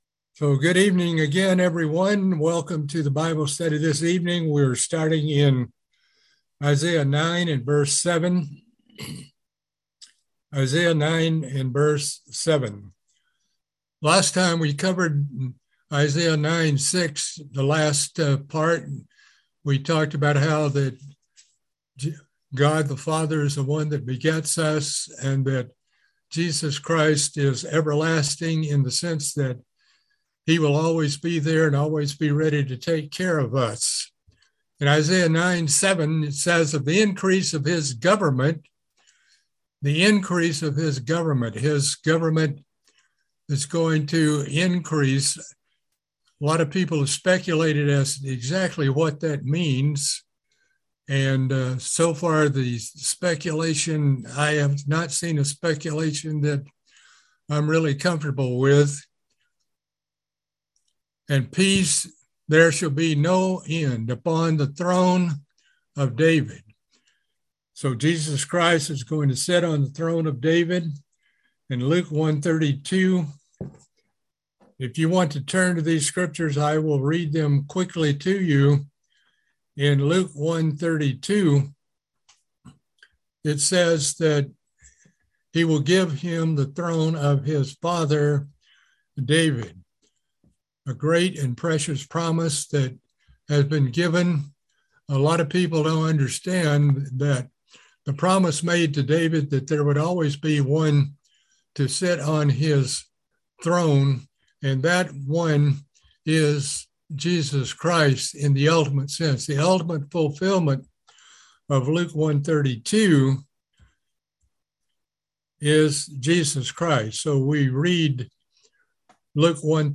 Book of Isaiah Bible Study - Part 9